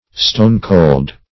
Stone-cold \Stone"-cold`\, a.